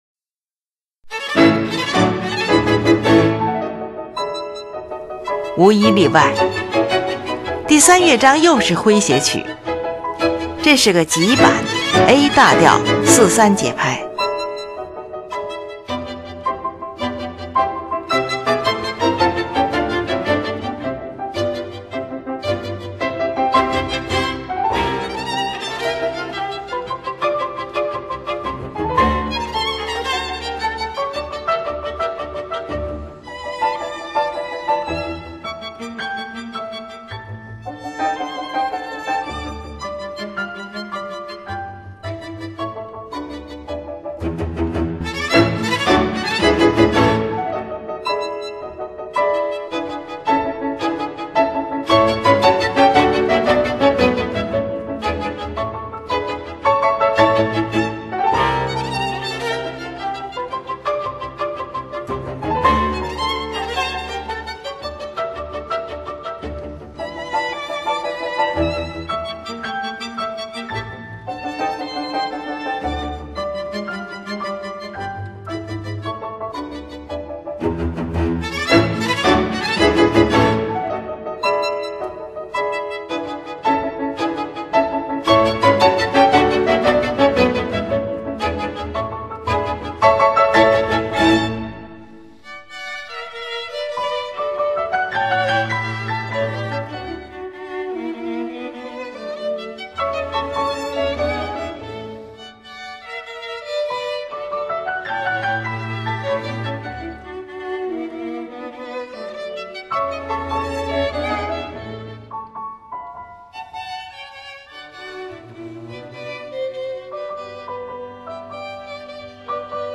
这是个急板，A大调，3/4节拍。